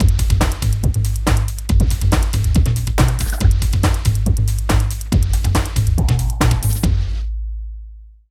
51 LOOP   -R.wav